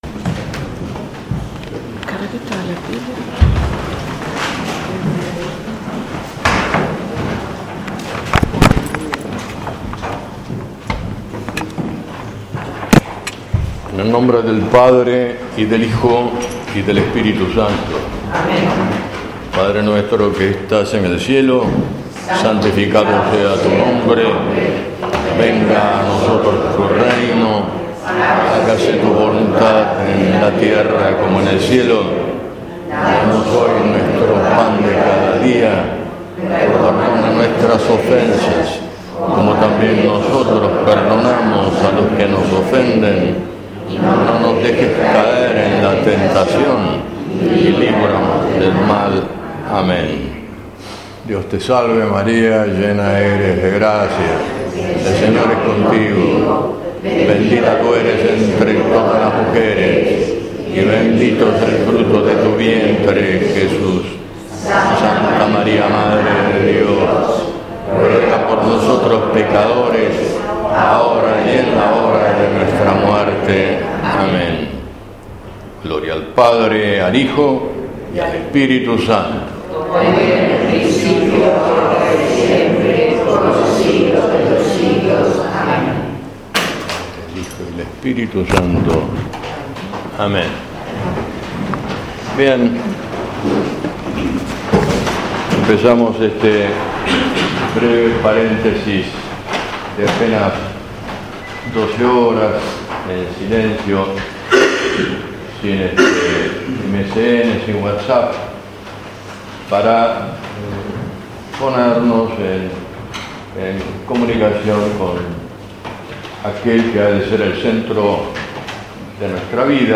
BREVE RETIRO ESPIRITUAL Predicador
1º Plática: Oración en el Huerto